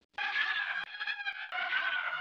west coast squeal.wav